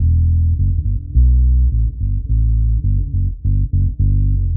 Index of /musicradar/dub-designer-samples/105bpm/Bass
DD_PBass_105_E.wav